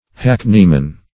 Meaning of hackneyman. hackneyman synonyms, pronunciation, spelling and more from Free Dictionary.
hackneyman.mp3